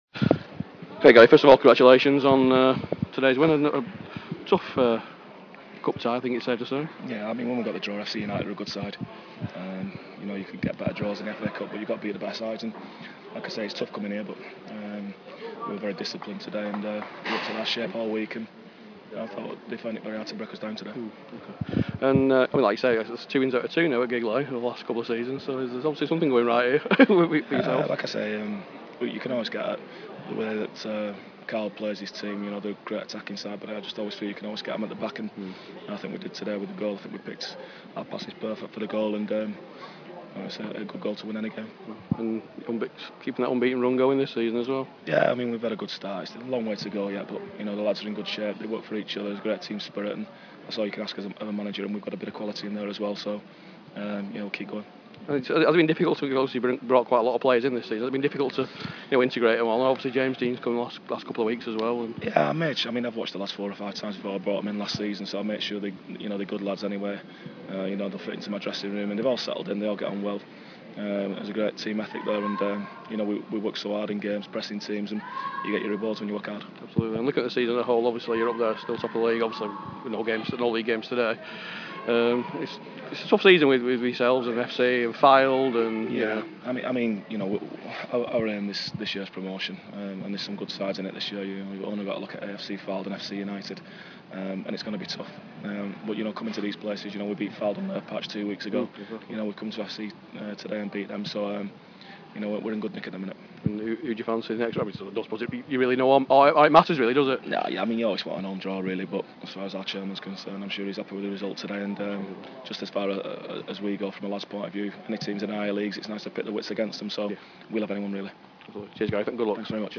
Post Match Interview - Garry Flitcroft - Chorley (h)